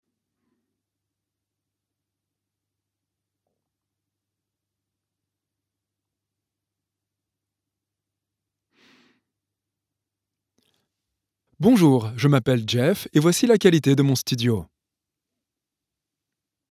Male
30s
French (Native) , American English , British English
Microphone: NEUMANN U87
Audio equipment: VOCAL BOOTH STUDIOBRICKS ONE - RME FIREFACE